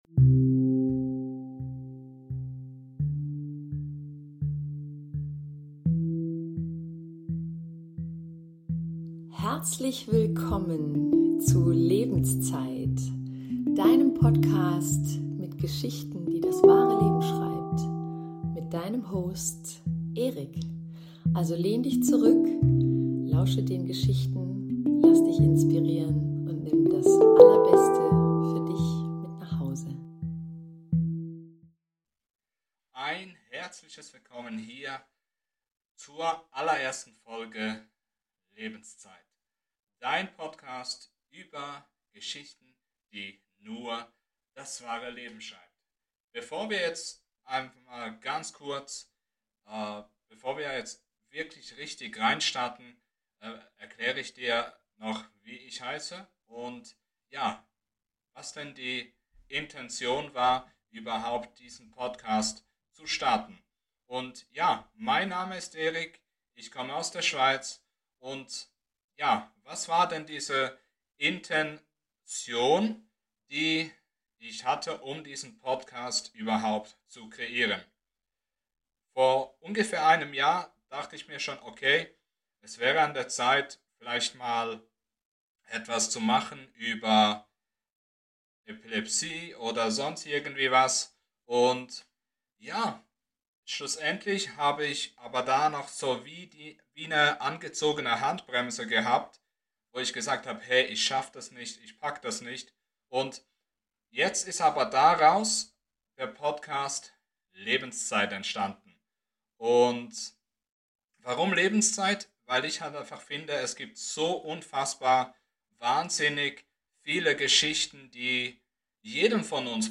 In diesem Podcast wird Musik gespielt